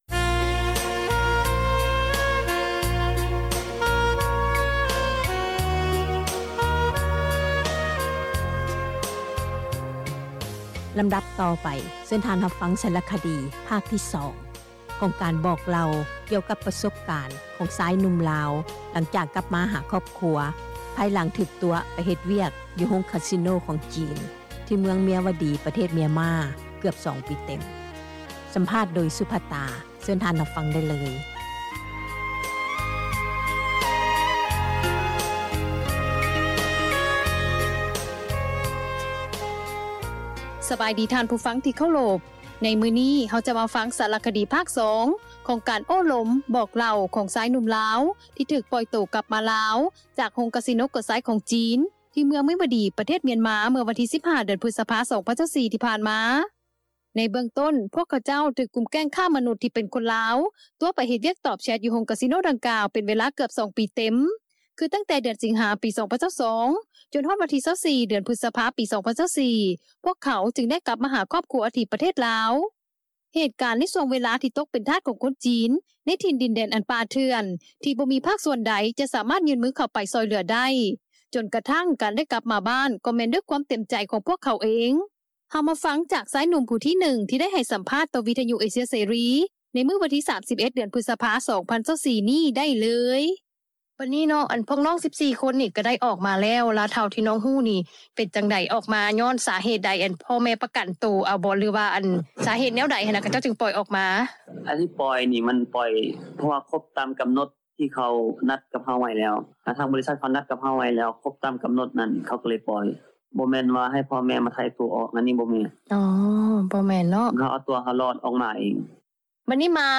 ສຳພາດເຫຍື່ອຄ້າມະນຸດ ທີ່ຖືກຕົວະໄປເຮັດວຽກ ໃນໂຮງກາສິໂນຂອງຈີນ ຢູ່ມຽນມາ ພາກ 2
ການບອກເລົ່າປະສົບການ ຂອງຊາຍໜຸ່ມລາວ ຫຼັງຈາກກັບມາ ຫາຄອບຄົວ ພາຍຫຼັງທີ່ຖືກຕົວະໄປເຮັດວຽກ ຢູ່ໂຮງກາສິໂນ ຂອງຈີນ ທີ່ເມືອງເມຍວະດີ ປະເທດມຽນມາ ເກືອບ 2 ປີເຕັມ.